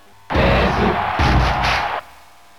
Ness Crowd Cheer in Dairantou Smash Brothers
Ness_Cheer_Japanese_SSB.ogg